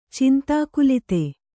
♪ cintākulite